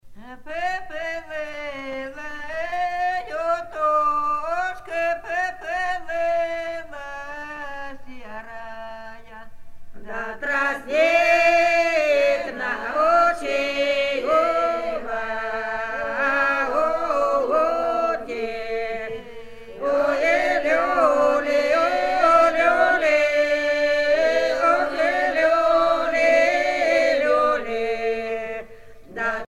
Traditional wedding of the south russia
Pièce musicale éditée